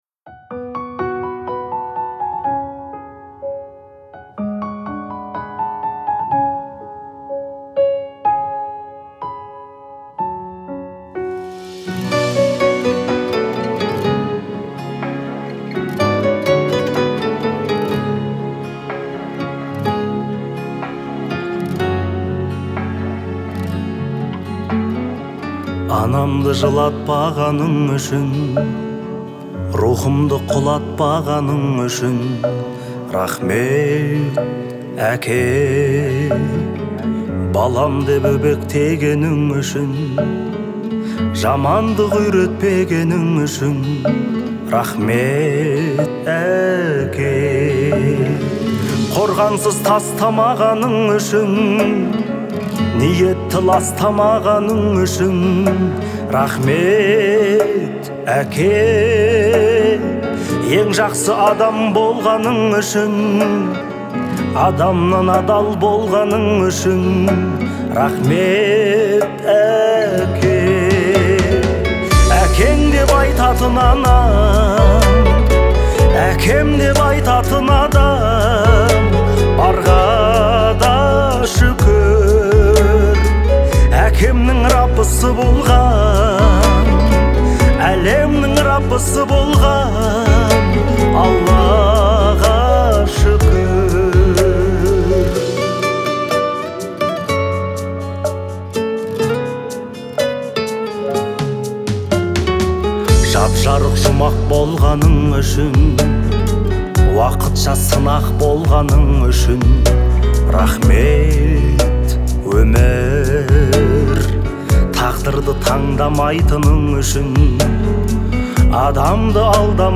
мелодичное исполнение